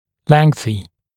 [‘leŋθɪ][‘лэнси]продолжительный